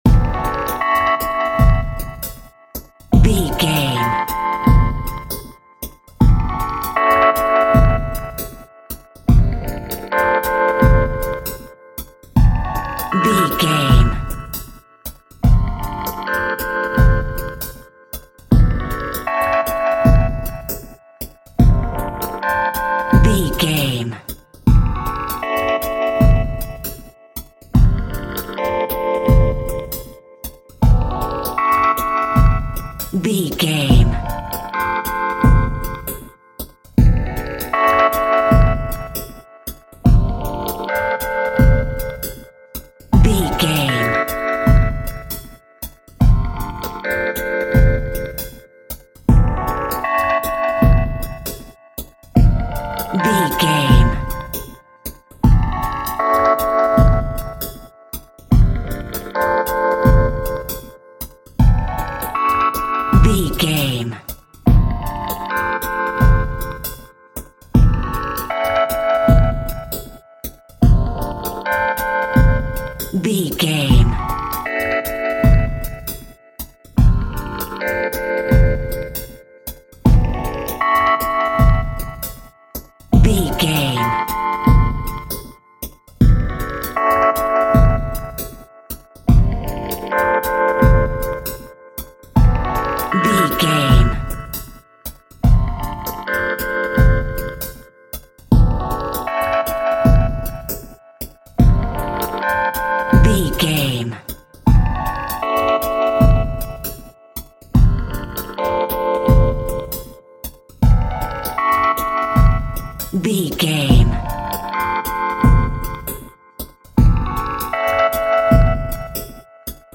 Aeolian/Minor
F#
dub
laid back
chilled
off beat
drums
skank guitar
hammond organ
transistor guitar
percussion
horns